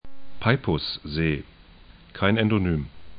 Peipussee 'paipʊs-ze: Peipsi järv 'pɛĭpsi jɛrf et